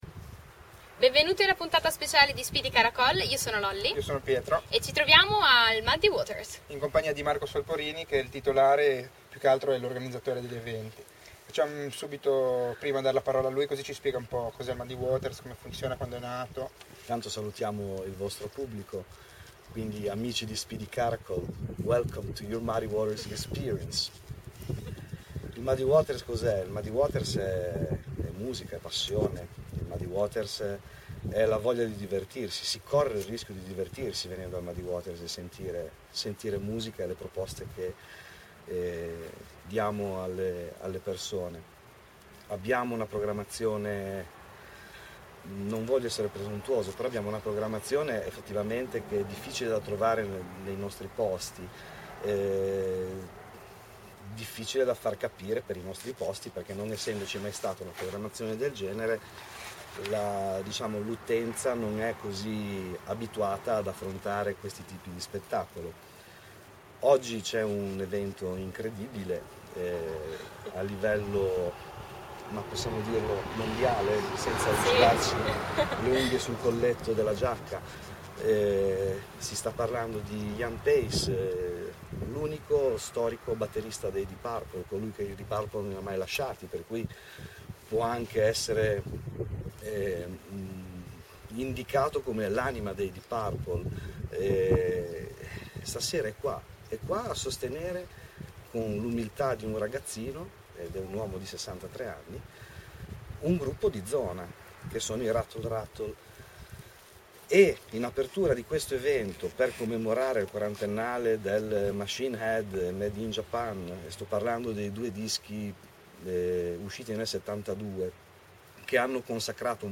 Intervista
Intervista effettuata fuori dal locale prima del concerto di Ian Paice, chitarrista dei Deep Purple.